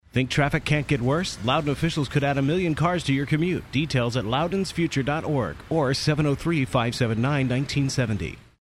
LoudounAd1male.mp3